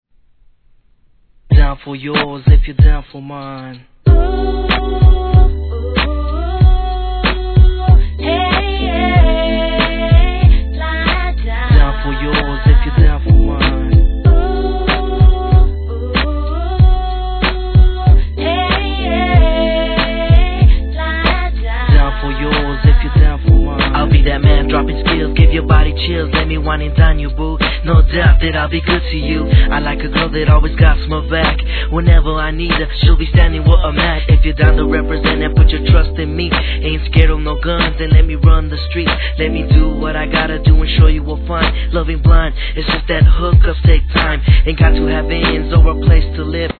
1. G-RAP/WEST COAST/SOUTH
万人受け間違い無しのTALK BOX & 女性コーラスが絡むメロ〜作!!